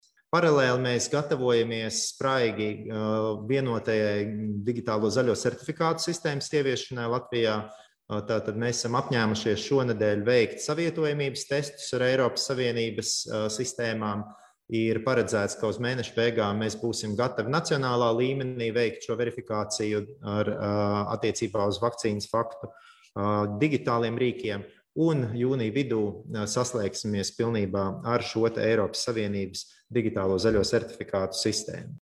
Par to cik tālu esam tikuši Latvijā stāsta Veselības ministrs Daniels Pavļuts: